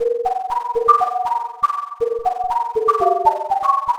Flitter Whistle.wav